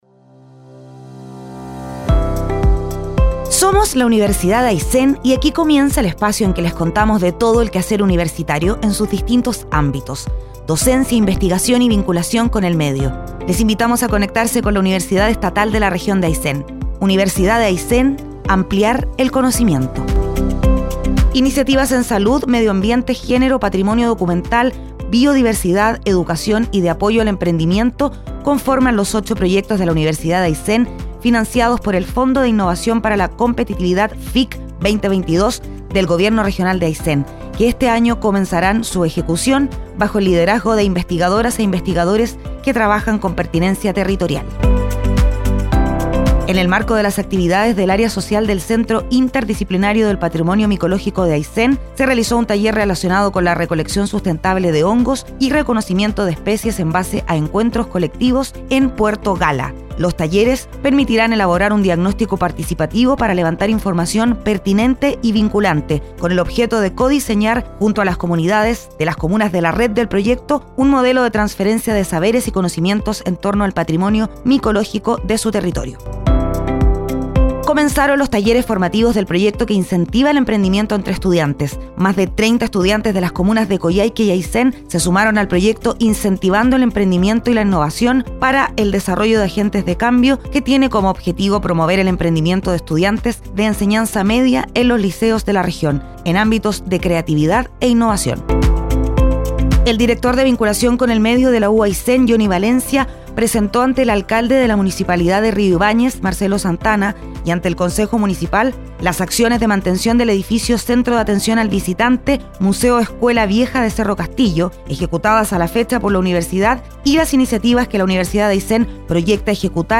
01-informativo-radial-uaysen-al-dia-junio-1-1.mp3